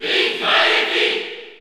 Category: Crowd cheers (SSBU) You cannot overwrite this file.
Dark_Pit_Cheer_French_PAL_SSBU.ogg